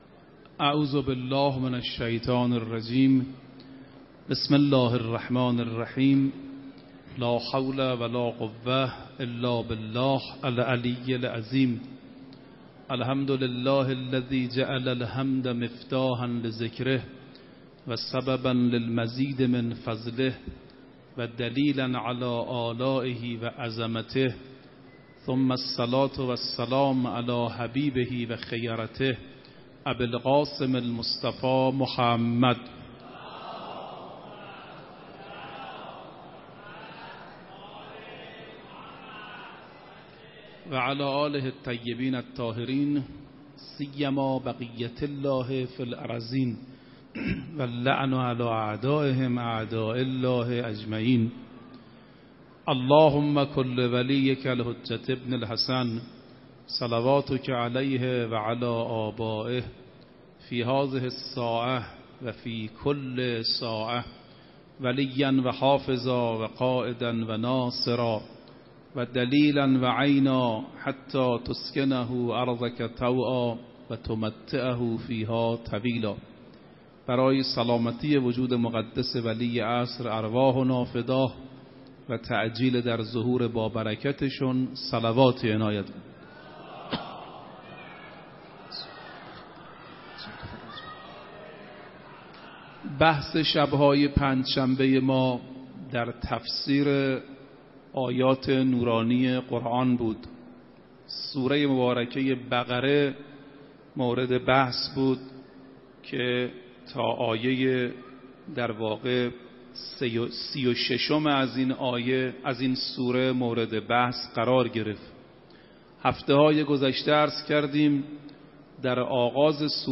30 فروردین 96 - حرم حضرت معصومه - سخنرانی